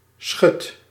Summary Description Nl-schut.ogg male voice pronunciation for " schut " .
Nl-schut.ogg